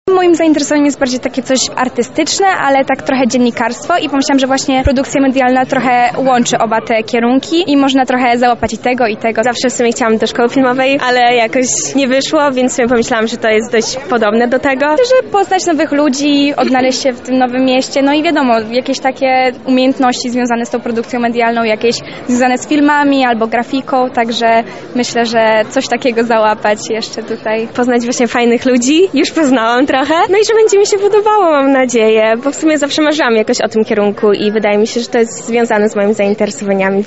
studentki sonda
studentki-sonda.mp3